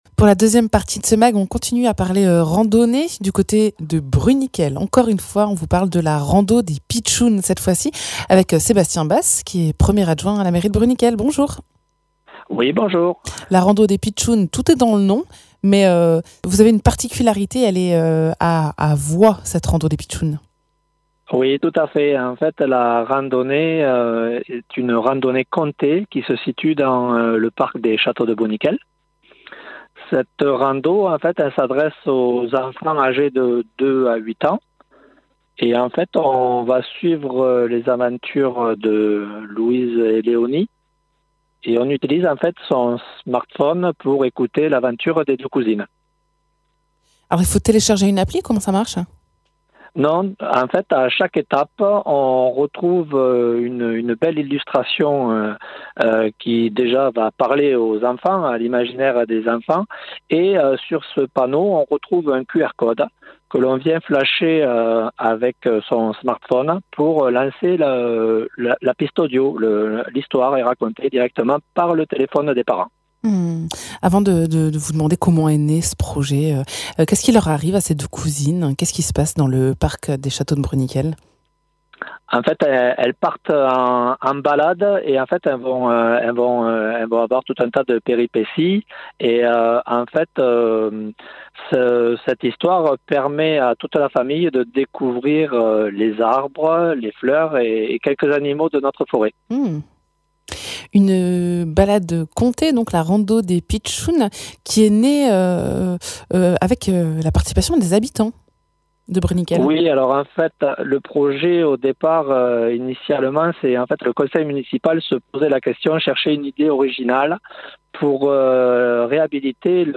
Invité(s) : Sébastien Basse, adjoint à la mairie de Bruniquel